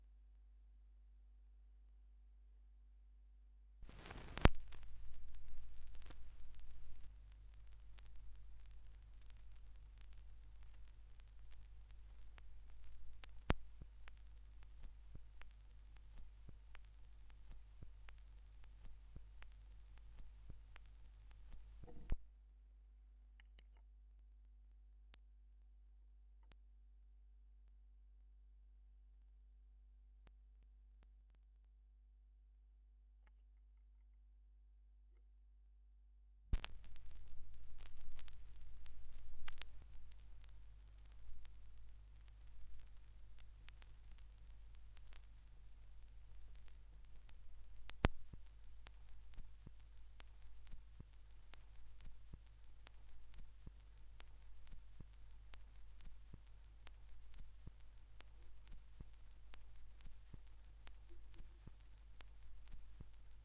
2017 Schallplattengeräusche (3)
Leere Phrasen (Leerstellen auf Schallplatten, die digitalisiert werden.)
Digitalisierung: Thorens TD 165 Special, Orthophon Pro System, Tascam HD-P2 (24 Bit, 48 KHz), Audacity